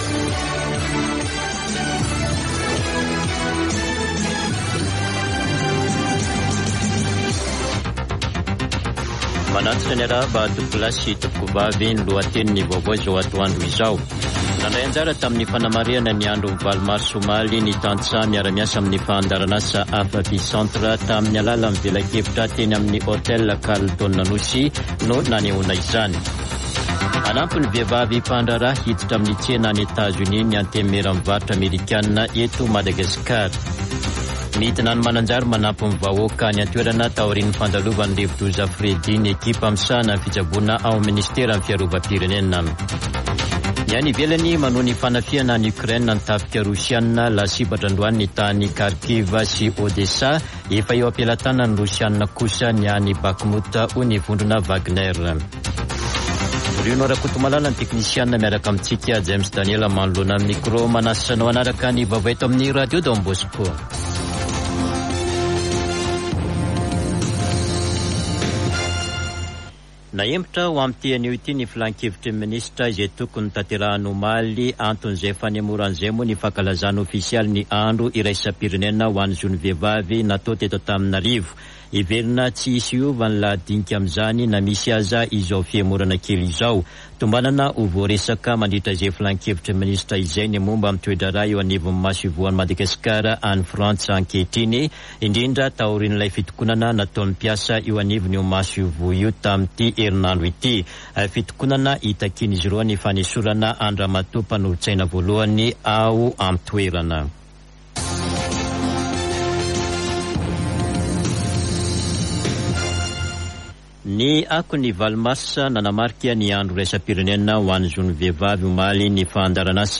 [Vaovao antoandro] Alakamisy 09 marsa 2023